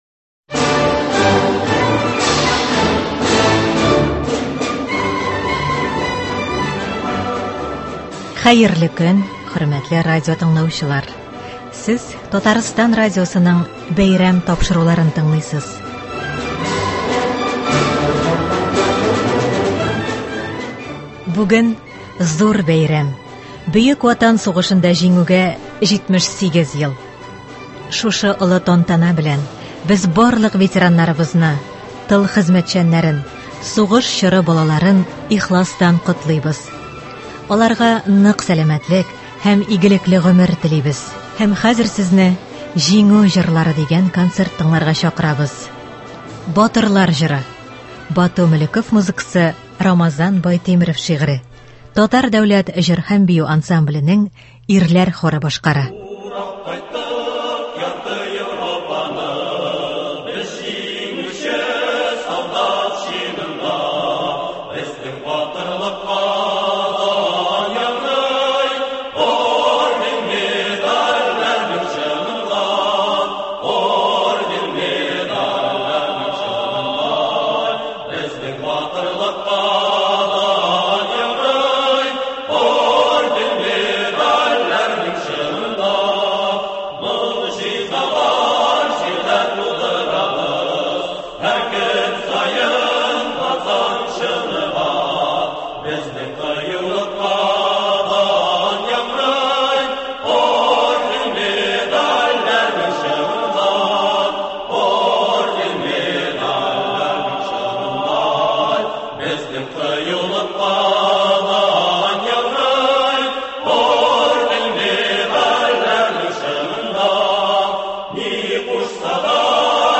Бәйрәм концерты.